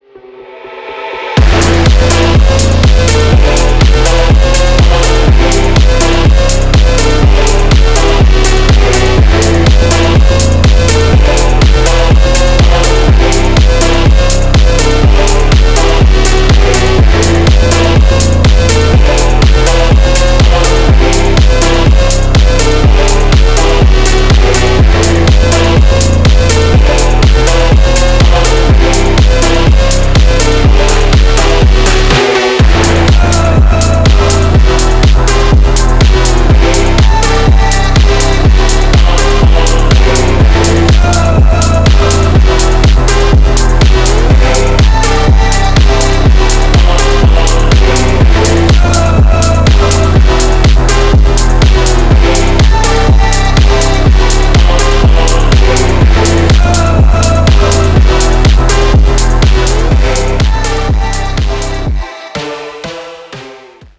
Архив Рингтонов, Клубные рингтоны